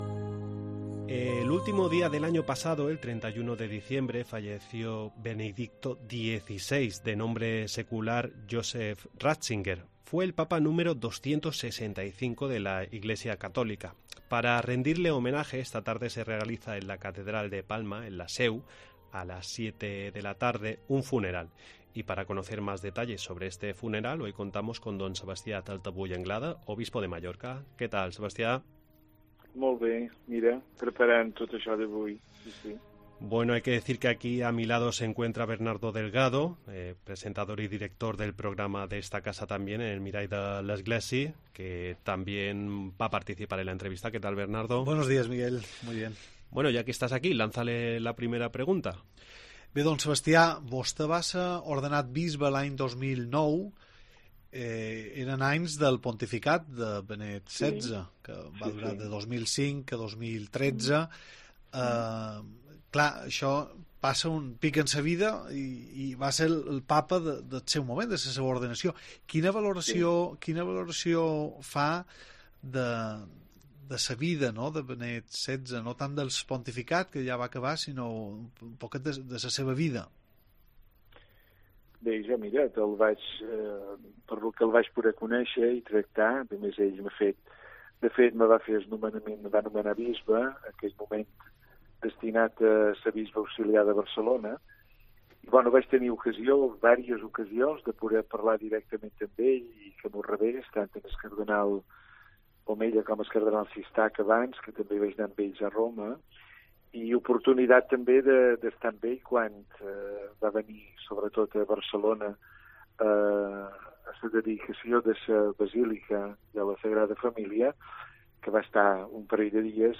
Contamos en el programa con Don Sebastià Taltavull, Obispo de Mallorca, con quien charlamos sobre la ceremonia que tendrá lugar esta tarde. Además, nuestro invitado también nos hace un perfil de Benedicto XVI, a quien pudo conocer personalmente.